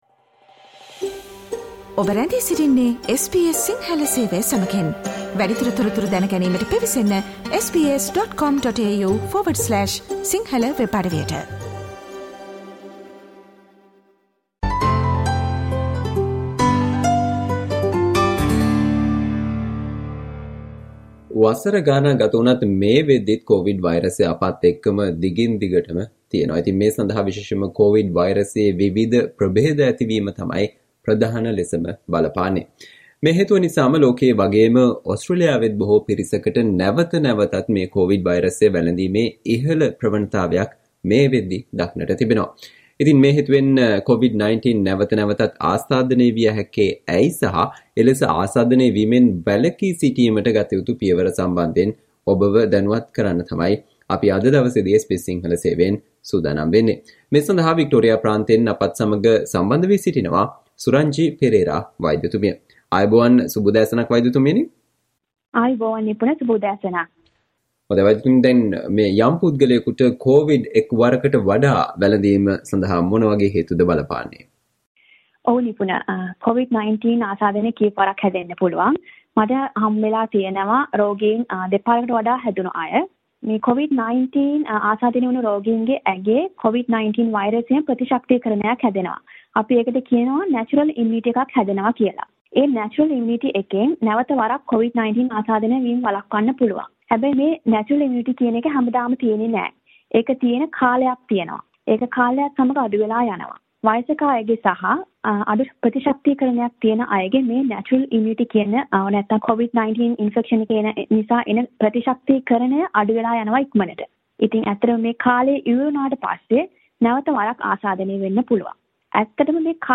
COVID- 19 නැවත නැවතත් ආසාදනය විය හැක්කේ ඇයි සහ එලෙස අසාදනය වීමෙන් වැළකී සිටීමට ගත යුතු පියවර සම්බන්ධයෙන් වන සාකච්චාවට සවන්දෙන්න